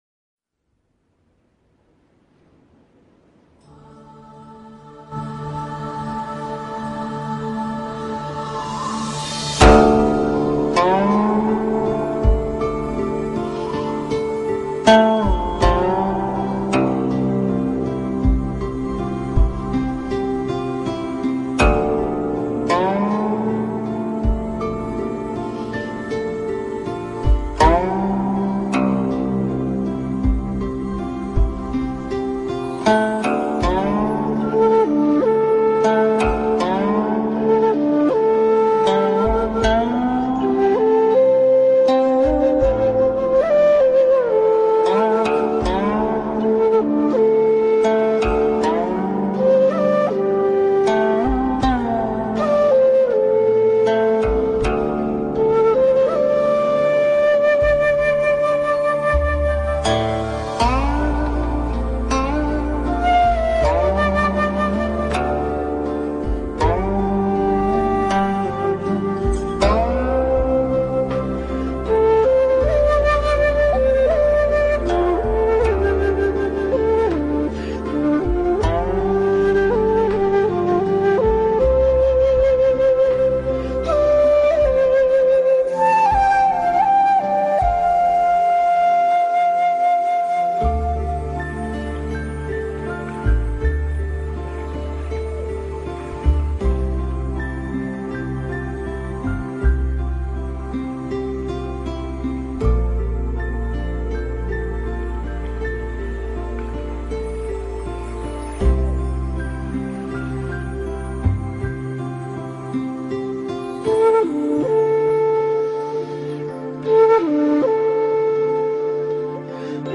佛音 冥想 佛教音乐 返回列表 上一篇： 弄戏--刘珂矣 下一篇： 语烟